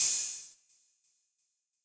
Metro Openhats [Crispy].wav